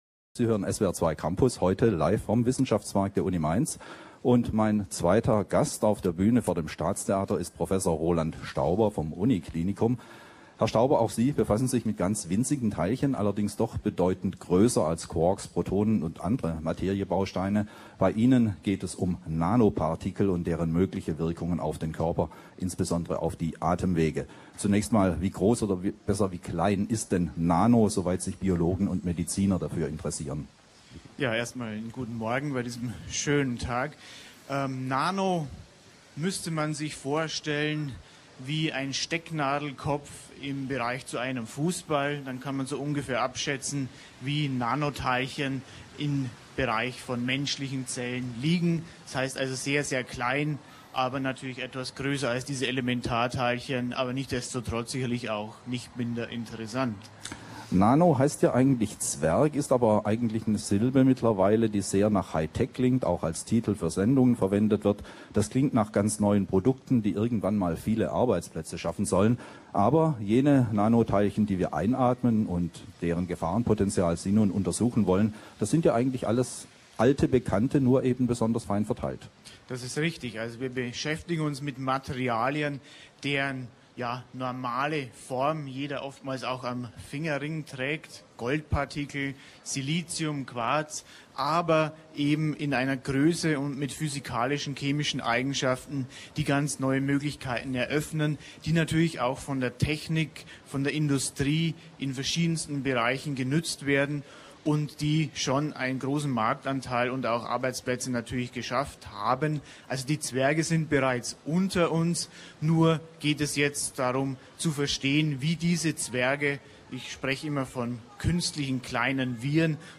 Eröffnungstalkrunde
Sendetermin: 13.09.2008, 10.05 Uhr, SWR2 Campus - LIVE, SWR2.